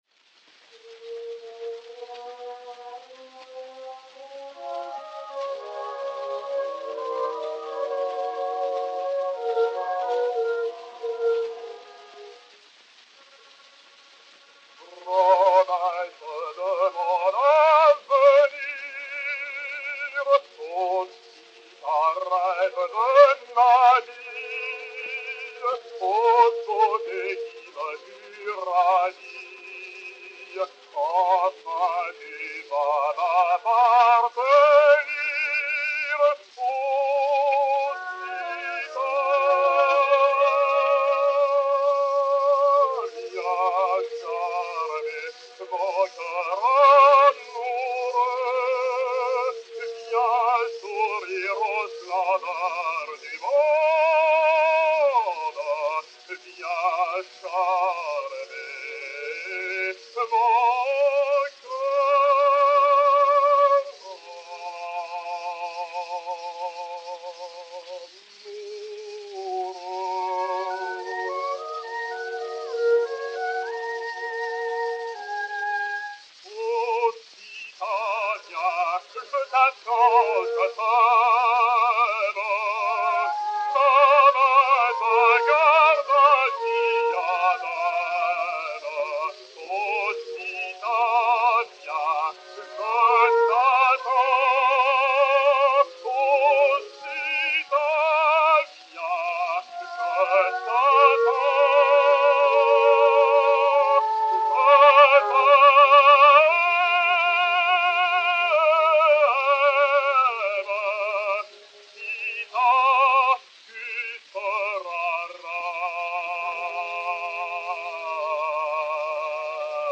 baryton français
baryton Orchestre
Arioso
Idéal saphir 6432, enr. vers 1912